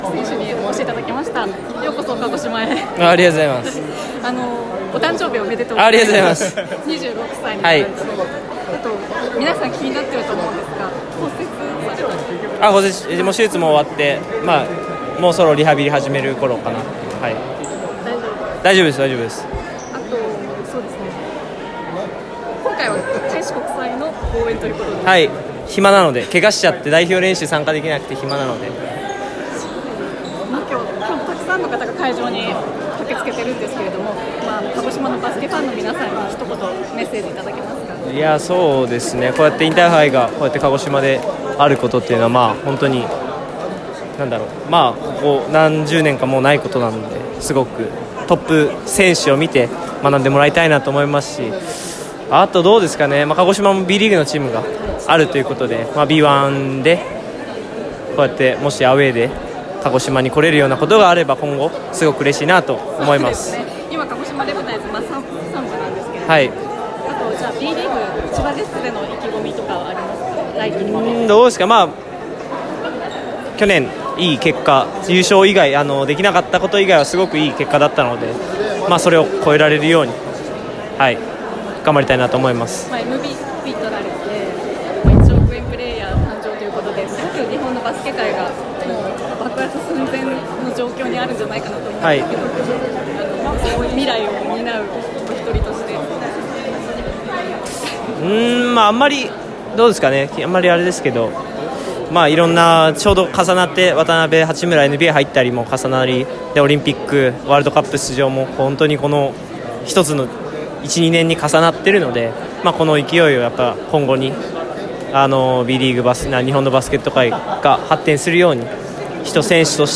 開志国際対福岡第一の試合前にインタビューに応じてくださいました！
富樫勇樹選手インタビュー（要約）